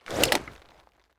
holster1.wav